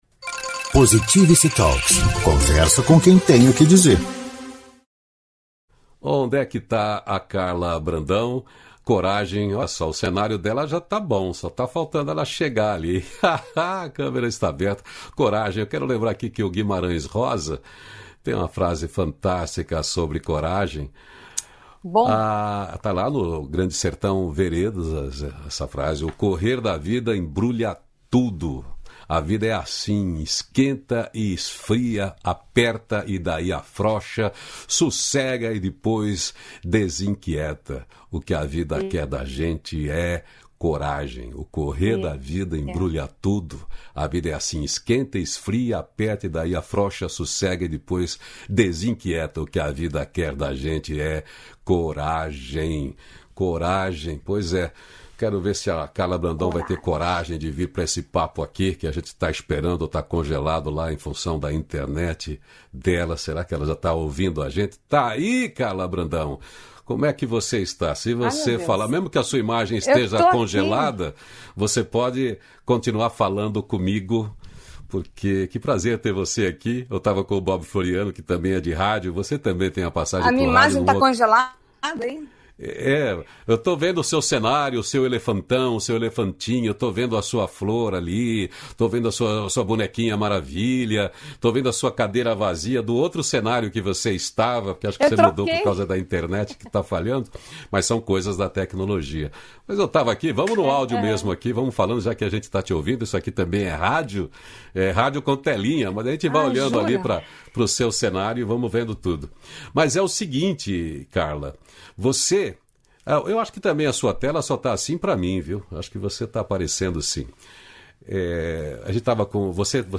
259-feliz-dia-novo-entrevista.mp3